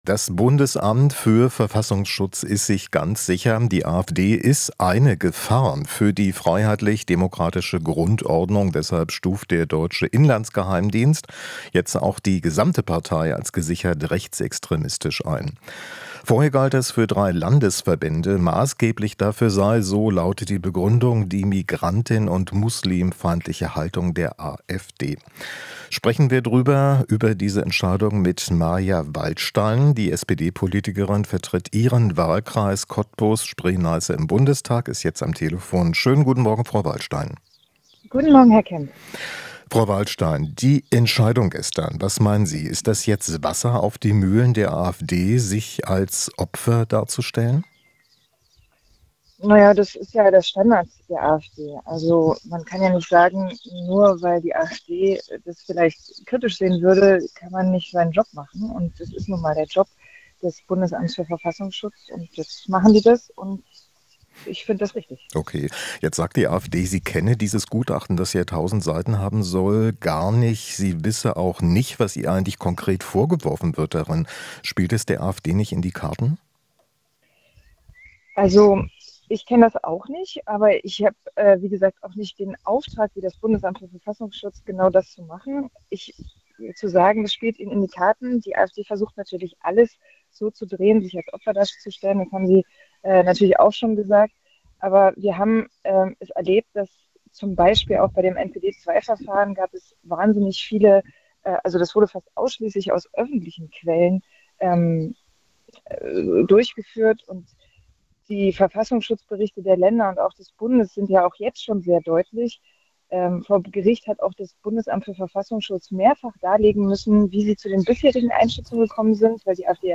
Interview - Wallstein (SPD): AfD-Verbot muss geprüft werden